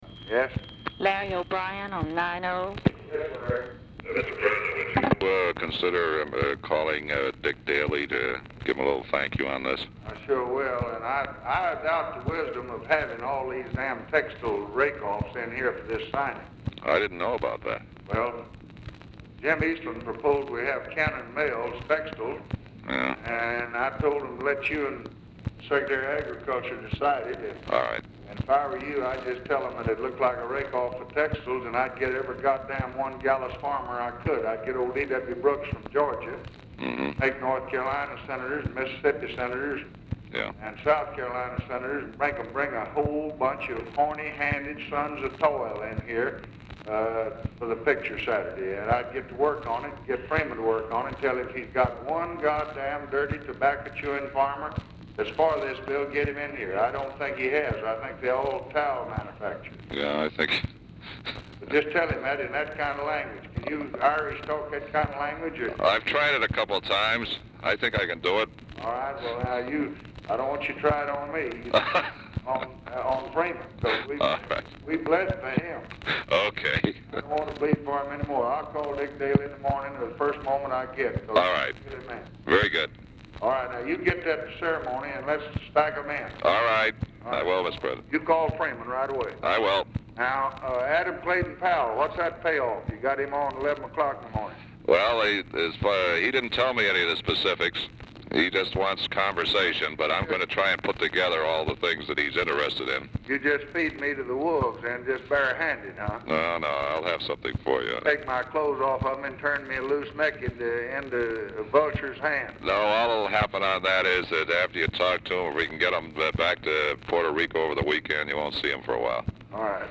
Oval Office or unknown location
LBJ IS APPARENTLY MEETING WITH BILL MOYERS? AT TIME OF CALL; SELECTED AS A HIGHLIGHT OF THE TELEPHONE CONVERSATION COLLECTION
Telephone conversation
Dictation belt